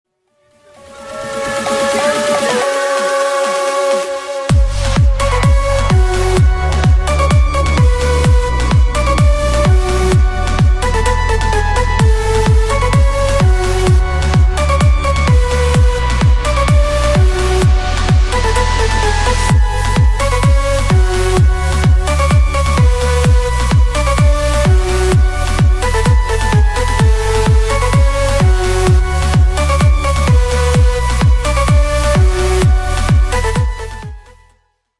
громкие
веселые
энергичные
house
Приятная клубная музыка.